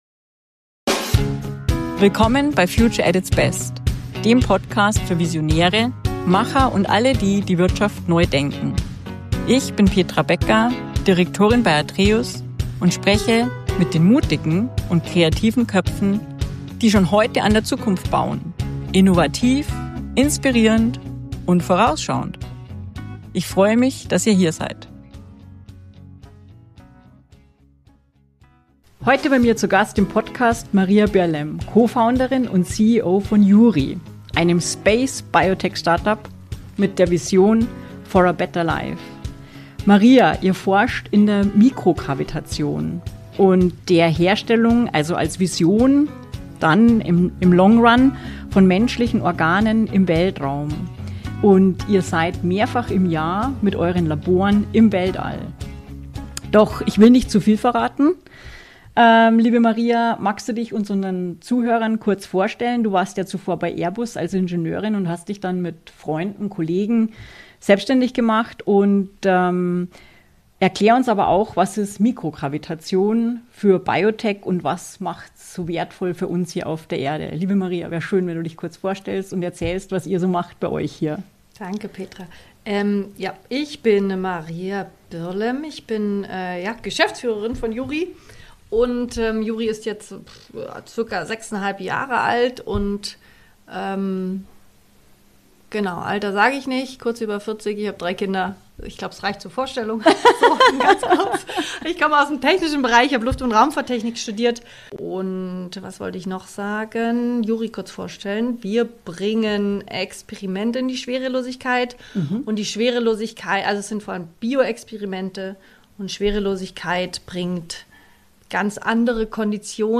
Ein interessanter Austausch über wissenschaftliche Pionierarbeit, unternehmerischen Mut und den Weg von einem jungen Startup hin zu einem Unternehmen, das durch kontinuierliche Partnerschaften und Zusammenarbeit mit Forschungseinrichtungen und Industrie nachhaltig wächst.